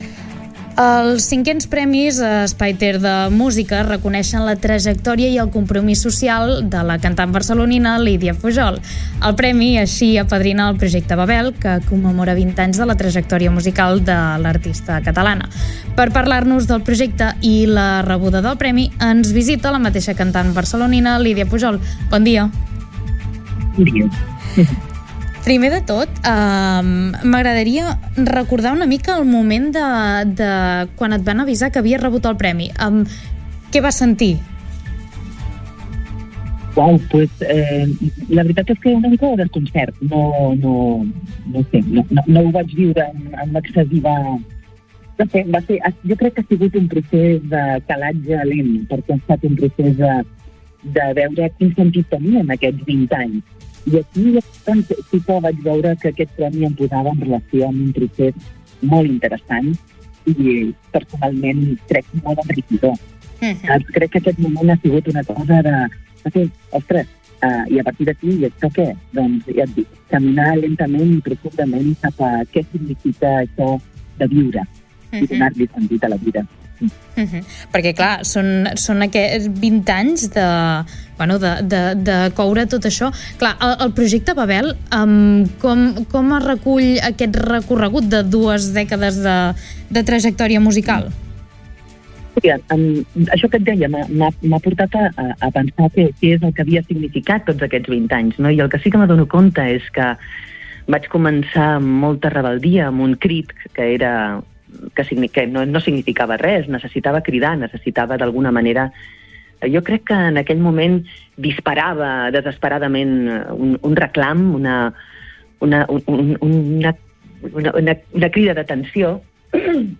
Entrevista-Lidia-Pujol-online-audio-converter.com_.mp3